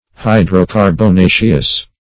Search Result for " hydrocarbonaceous" : The Collaborative International Dictionary of English v.0.48: Hydrocarbonaceous \Hy`dro*car`bo*na"ceous\, a. Of the nature, or containing, hydrocarbons.